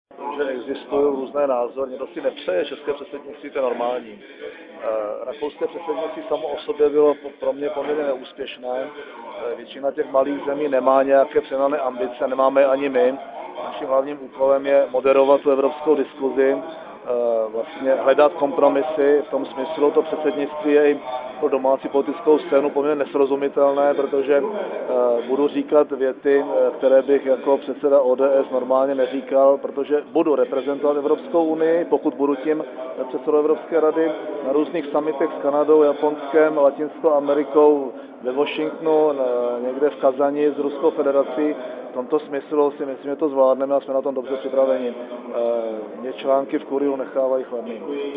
Níže uvádíme zvukový záznam premiérova vyjádření pro média v Poslanecké sněmovně 22.10.2008